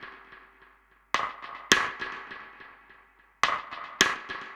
Index of /musicradar/dub-drums-samples/105bpm
Db_DrumKitC_EchoSnare_105-02.wav